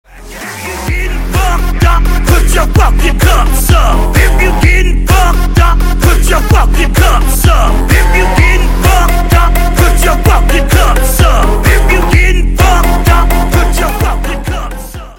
• Качество: 320, Stereo
мужской голос
Trap
клубняк
Клубный рингтон в стиле трэп